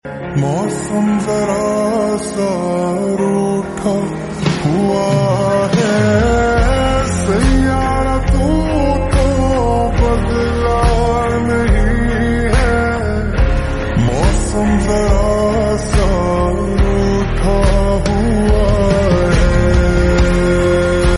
Heavy rain